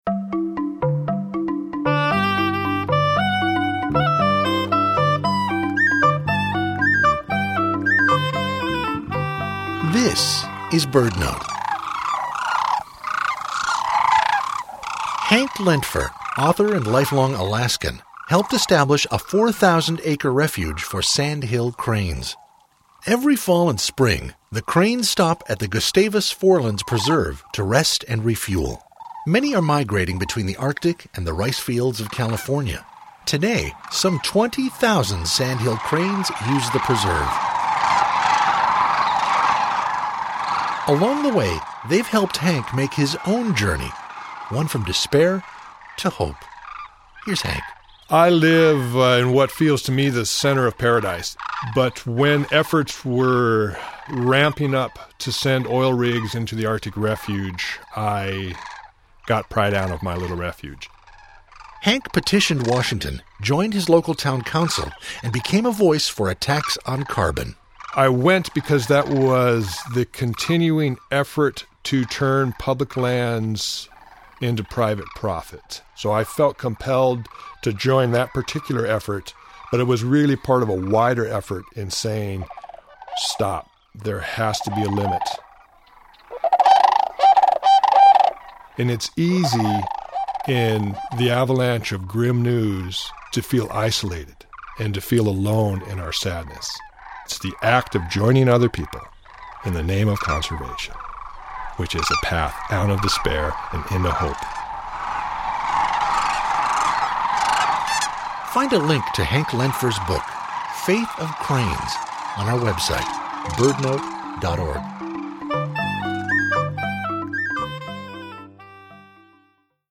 Here's a link to a BirdNote interview which aired on NPR.